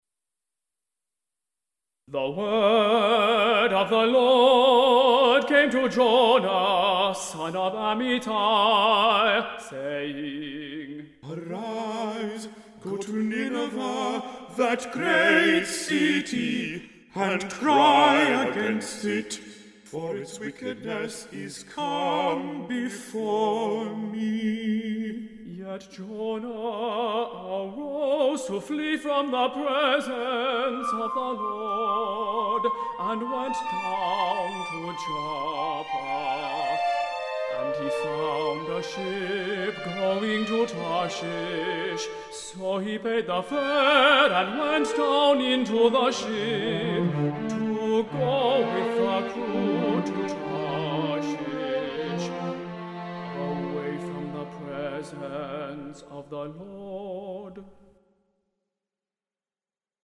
Instrumentation:
Flute
Trumpets (C) 1 & 2
Percussion (1 player)
Narrator (Tenor)
SATB Choir
Cellos
Piano